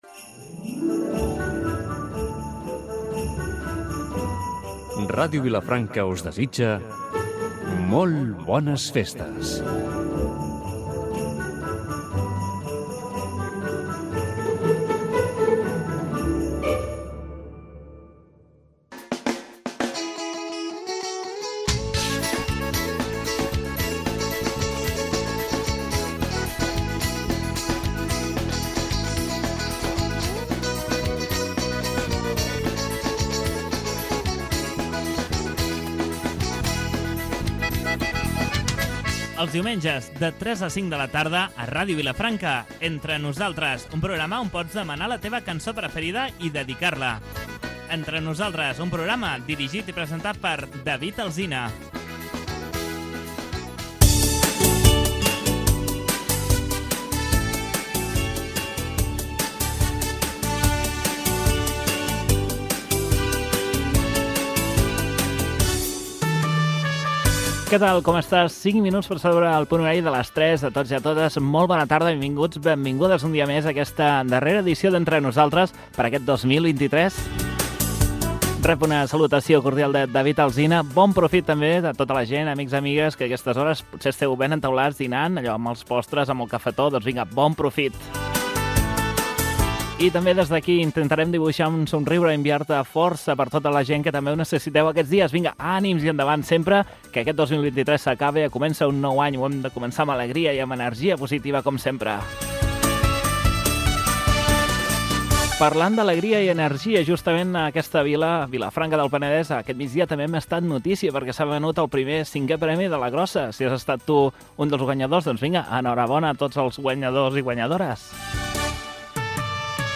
Indicatiu nadalenc de l'emissora, careta, hora, presentació del programa, telèfons de participació i primera trucada telefònica
FM